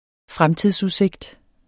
Udtale [ ˈfʁamtiðs- ]